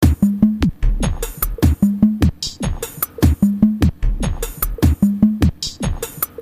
描述：阿姆斯特丹中央车站的一般喋喋不休，使用诺基亚Lumia 1520手机录制。
Tag: 震颤 阿姆斯特丹 氛围 车站 一般来说 现场记录 中央